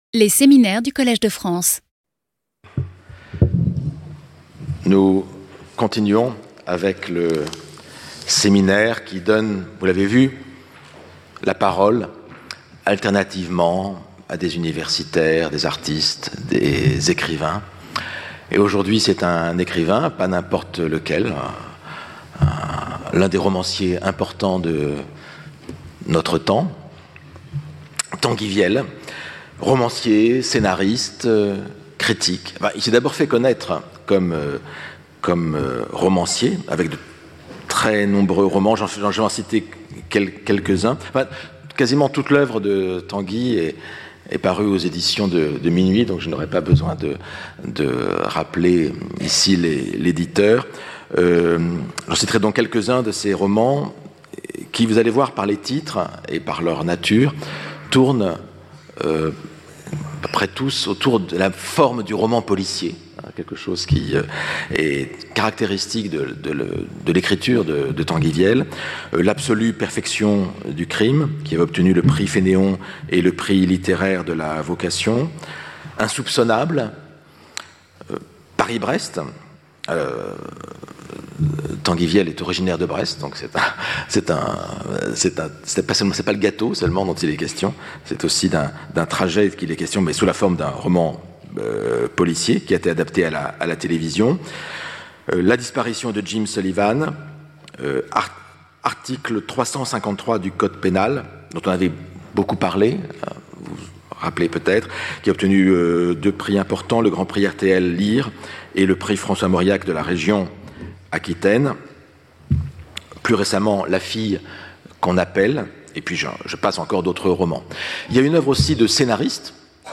Séminaire - Tanguy Viel : Lire pour écrire. 06 - Comment lire ? (suite) - Sens et non-sens entre terreur et liberté.